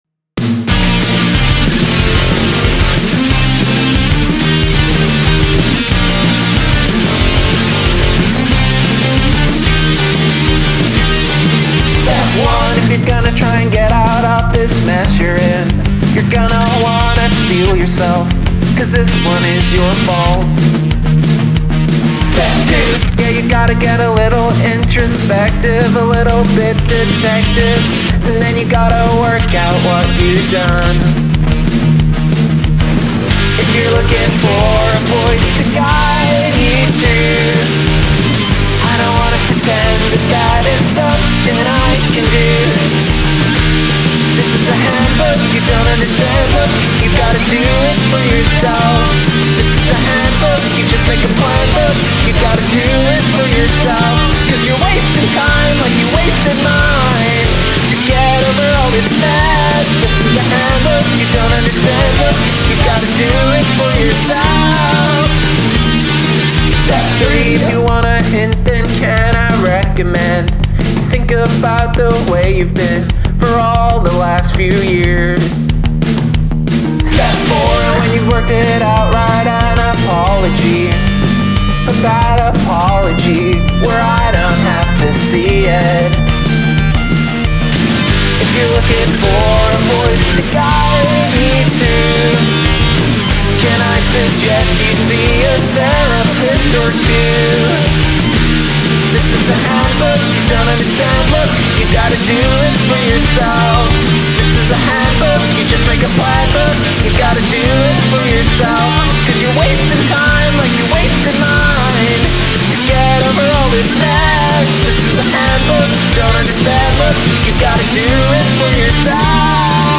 I like that tempo changeup in the bridge; it's fun!